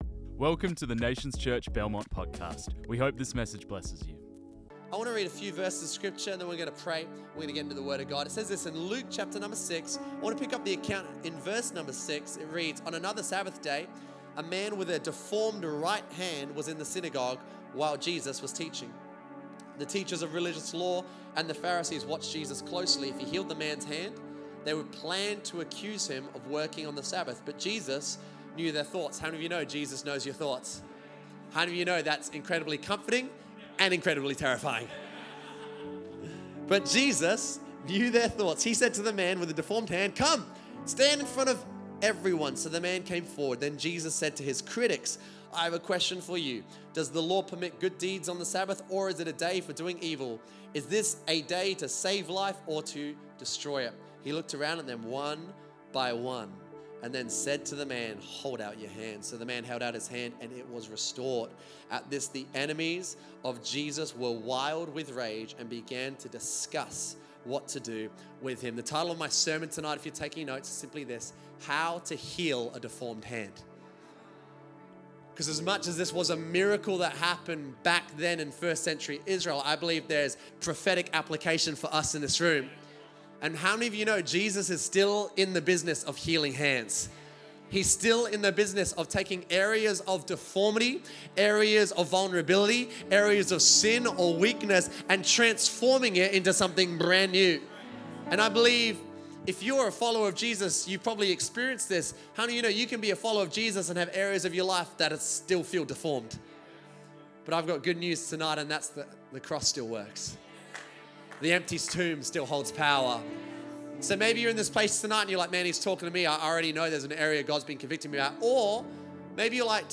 This message was preached on 30 March 2025.